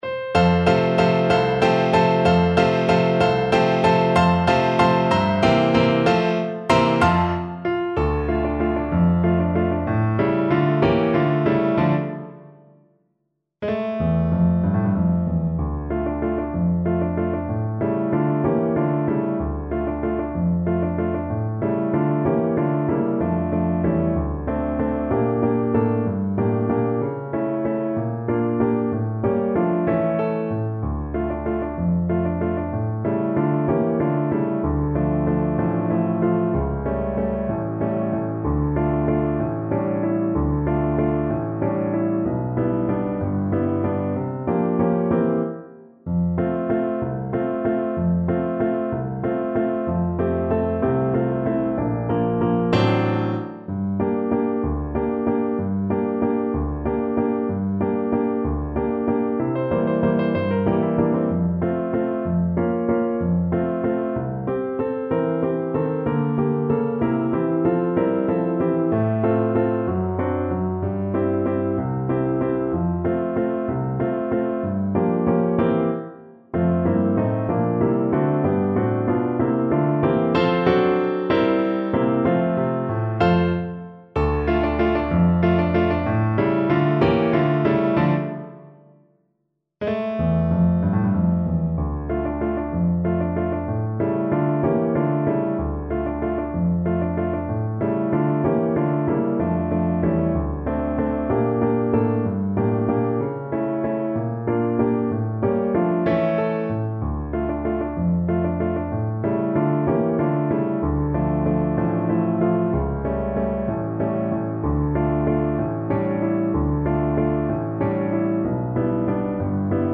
Tempo di Valse .=63
3/4 (View more 3/4 Music)
Classical (View more Classical Flute Music)